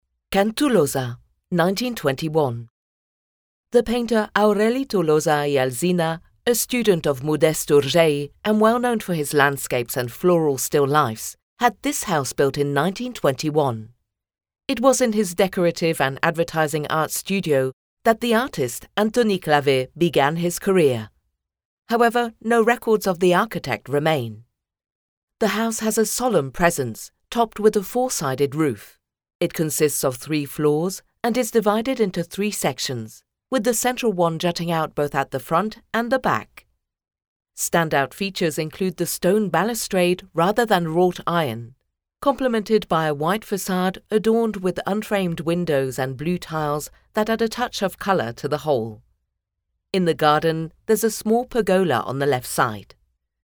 • RUTA MODERNISTA AUDIOGUIADA